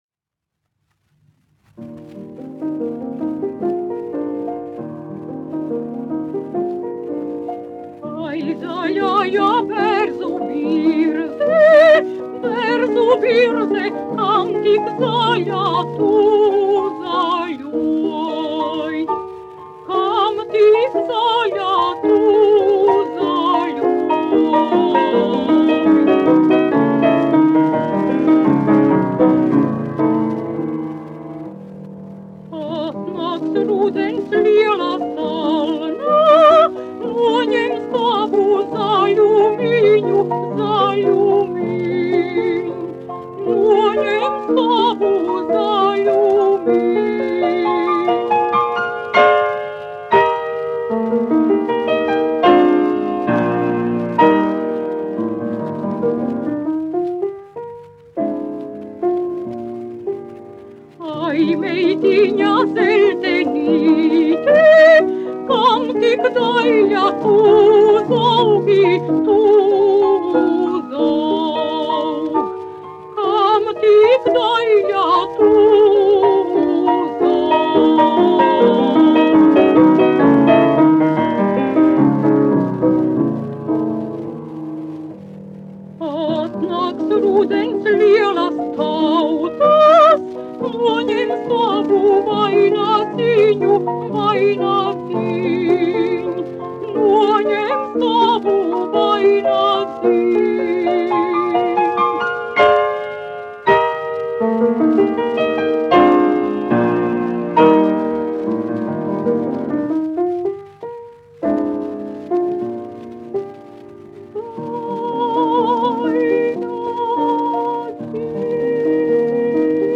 Volfgangs Dārziņš, 1906-1962, aranžētājs
1 skpl. : analogs, 78 apgr/min, mono ; 25 cm
Latviešu tautasdziesmas
Latvijas vēsturiskie šellaka skaņuplašu ieraksti (Kolekcija)